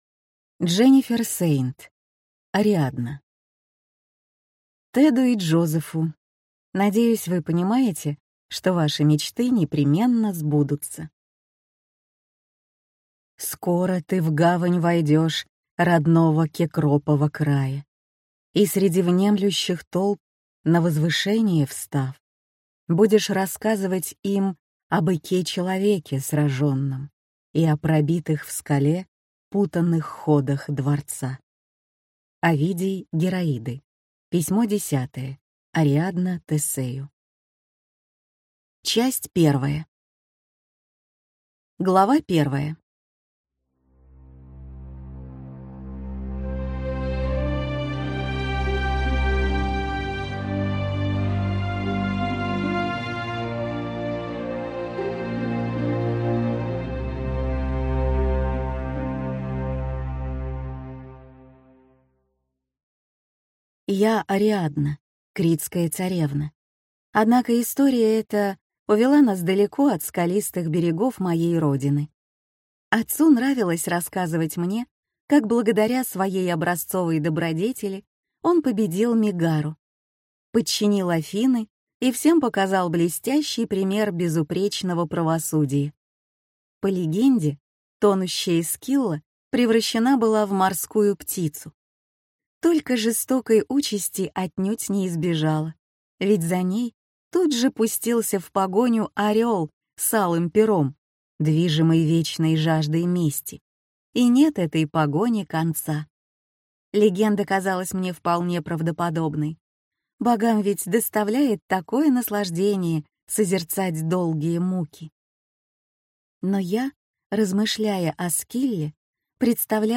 Аудиокнига Ариадна | Библиотека аудиокниг